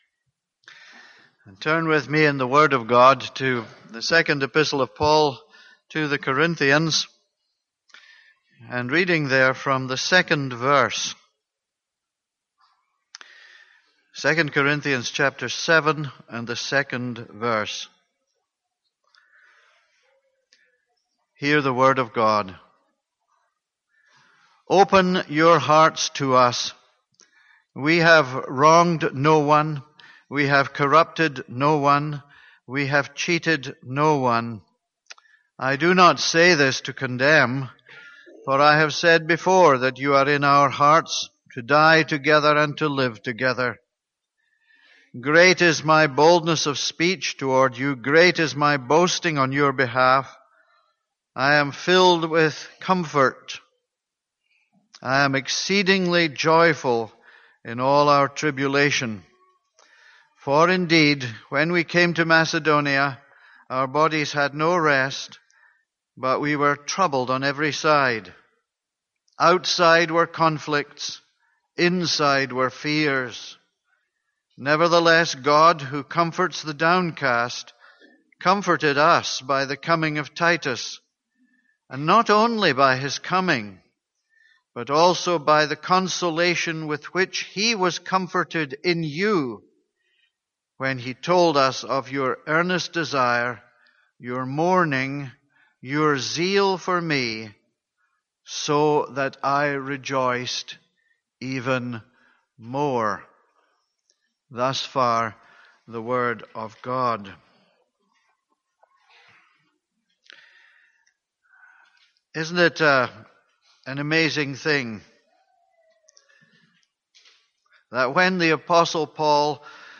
This is a sermon on 2 Corinthians 7:5-7.